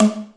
带泡沫的浴缸
描述：有人正在洗澡。
标签： 湿 浴缸 飞溅
声道立体声